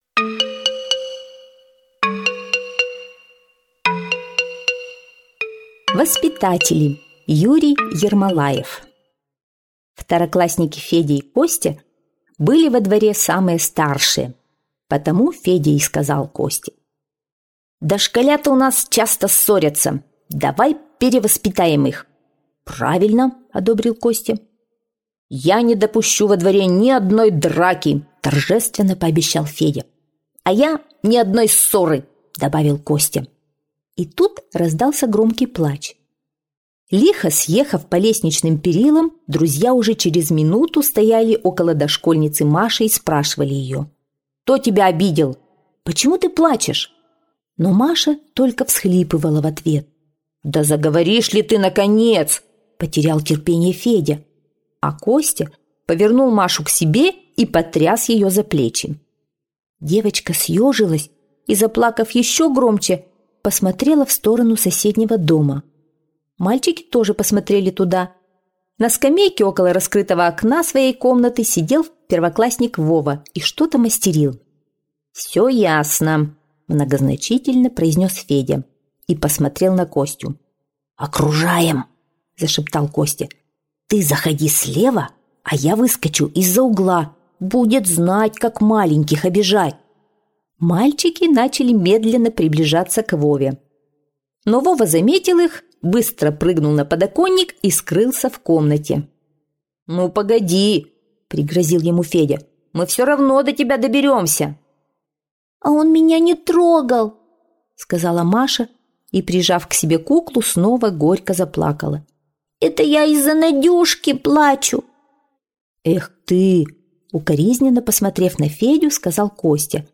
На данной странице вы можете слушать онлайн бесплатно и скачать аудиокнигу "Воспитатели" писателя Юрий Ермолаев.